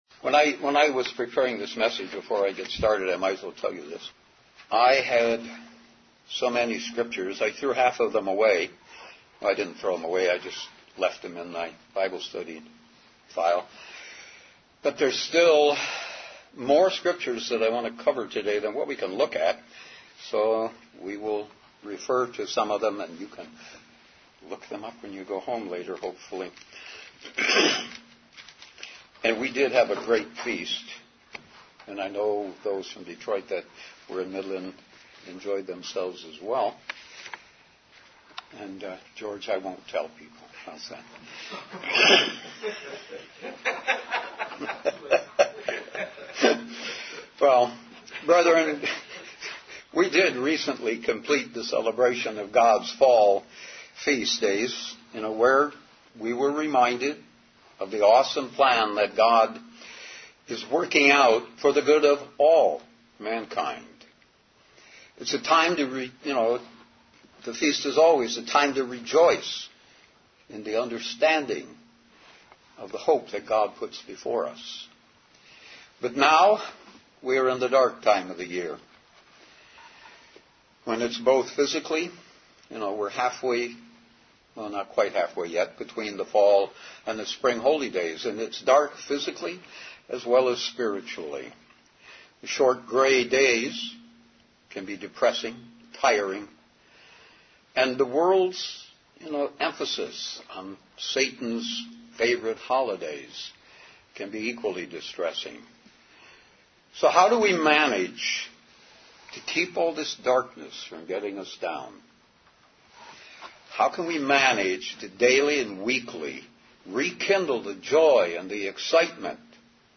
Sermons
Given in Detroit, MI Ann Arbor, MI